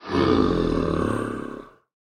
Minecraft Version Minecraft Version 25w18a Latest Release | Latest Snapshot 25w18a / assets / minecraft / sounds / mob / zombie_villager / say3.ogg Compare With Compare With Latest Release | Latest Snapshot